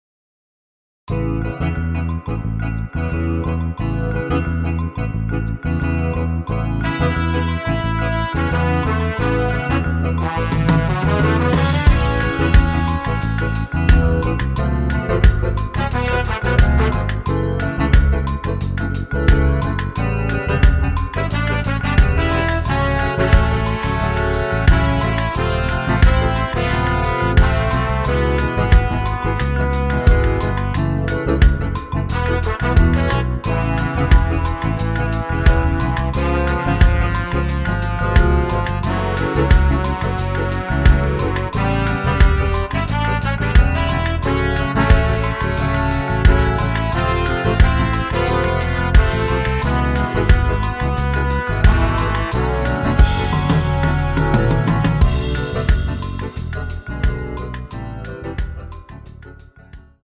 원키에서(-7)내린 MR입니다.
엔딩이 길어 축가에 사용 하시기 좋게 엔딩을 짧게 편곡 하였습니다.(원키 코러스 버전 미리듣기 참조)
앞부분30초, 뒷부분30초씩 편집해서 올려 드리고 있습니다.
중간에 음이 끈어지고 다시 나오는 이유는